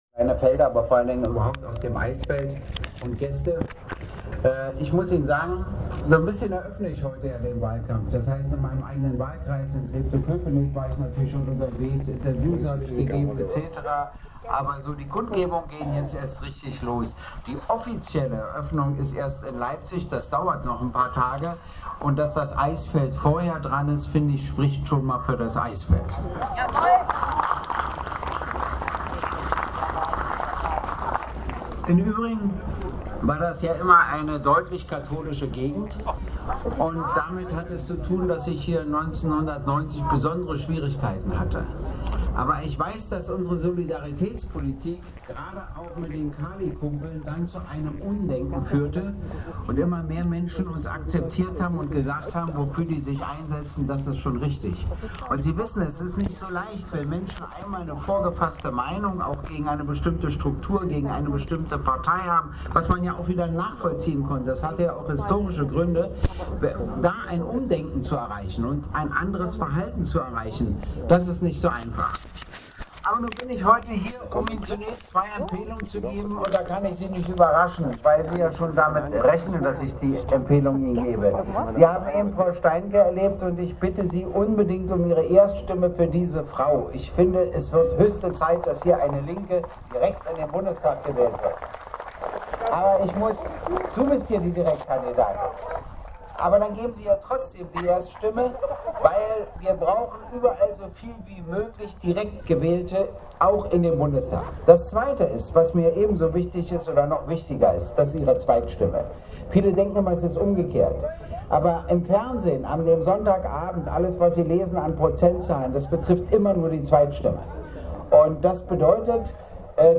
Mit viel Beifall wurde Fraktionschef Gregor Gysi bei seiner Rede immer wieder bedacht....
Gregor Gysi sprach zur Kriegsbeteiligung, die von den LINKEN abgelehnt wird und begründete auch, warum. Weiter ging es um das Bildungssystem, Renten, Mietrecht, Angleichung Ost-West, Frauenlöhne u.a. Die Eichsfelder Nachrichten haben die Rede aufgezeichnet und als mp3 auf dieser Seite veröffentlicht, die sich jeder dann selbst anhören kann.